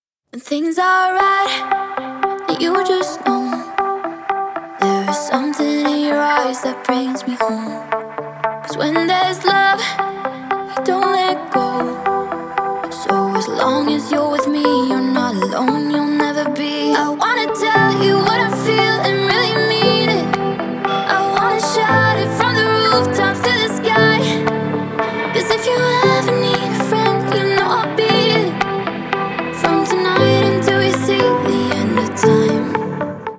• Качество: 128, Stereo
спокойные
красивый женский голос
Мелодичная музыка на звонок с красивым женским вокалом